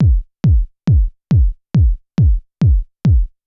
Index of /90_sSampleCDs/Best Service ProSamples vol.54 - Techno 138 BPM [AKAI] 1CD/Partition C/SHELL CRASHE